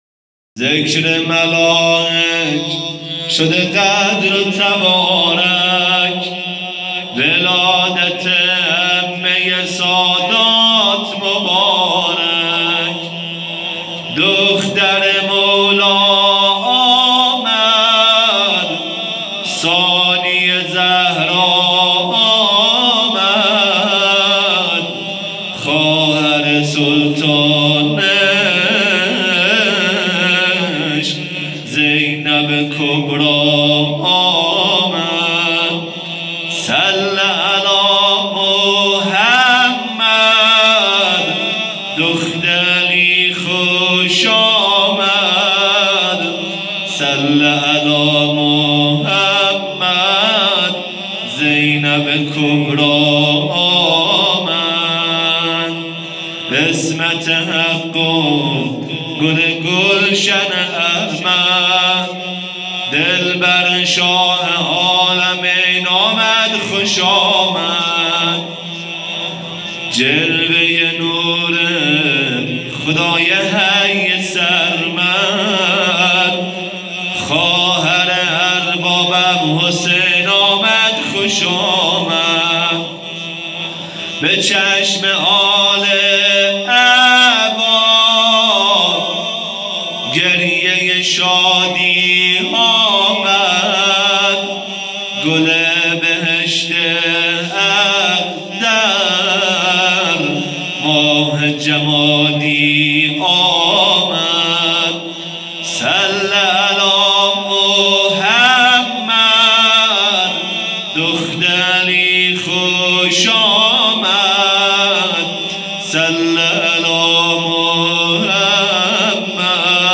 جشن میلاد حضرت زینب کبری و سالروز پیروزی انقلاب اسلامی
به مناسبت میلاد حرت زینب سلام الله علیها